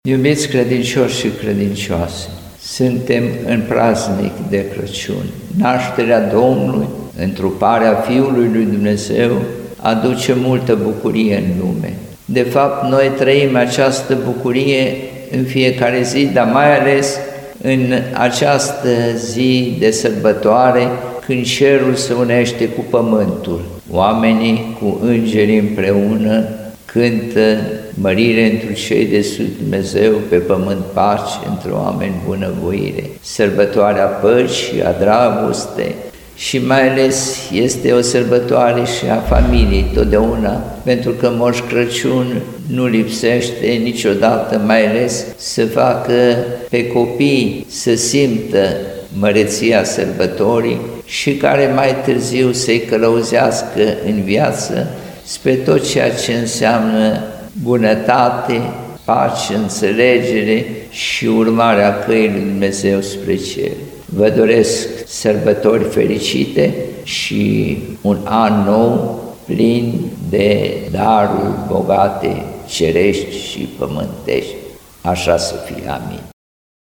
Mesajul de Crăciun al Înaltpreasfinției sale Timotei Seviciu, Arhiepiscop al Aradului